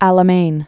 (ălə-mān), El